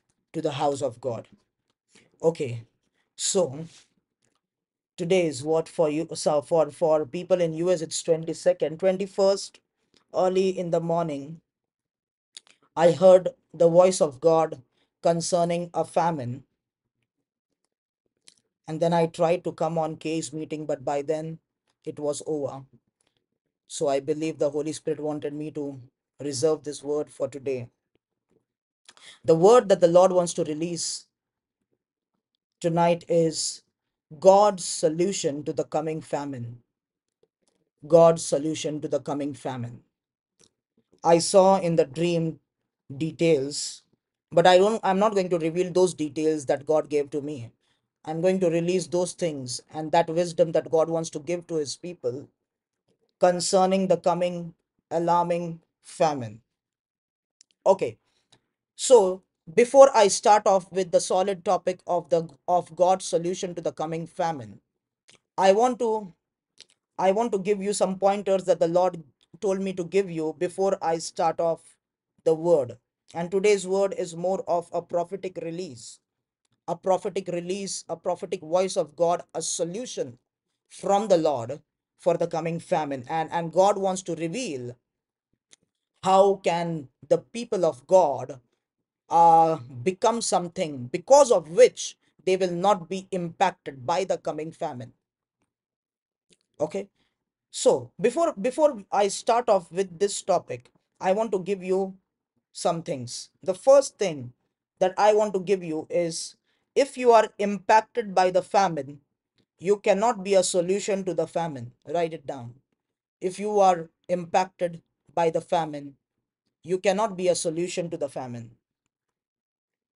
audio sermons - God's solution to the coming famine | Refined & Stored Products